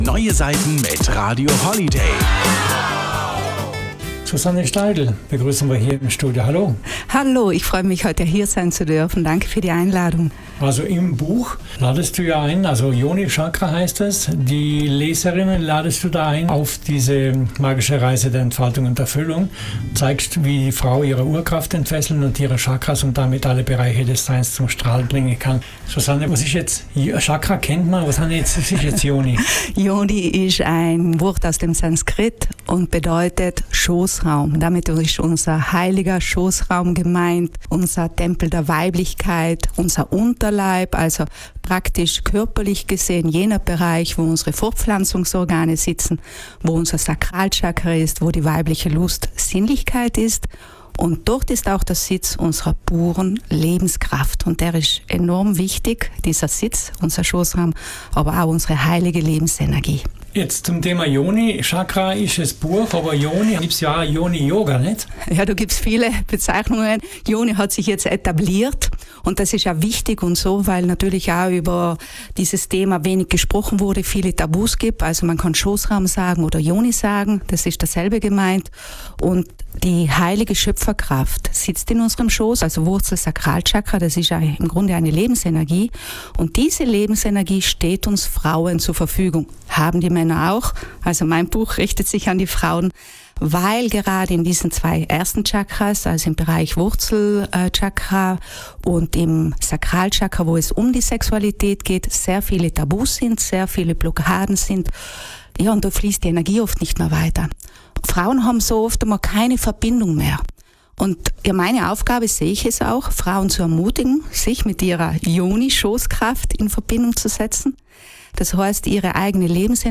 Yoni-Chakra-Interview-Radio-Holiday.mp3